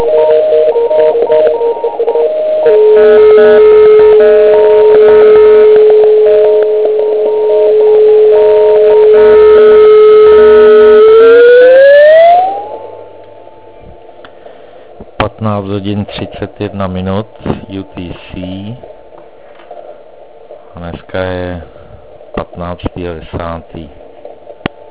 Zkusil jsem nejprve maják OK0EU na 7038.5 KHz.
V tuto dobu však jeho 1W byl jako 1kW.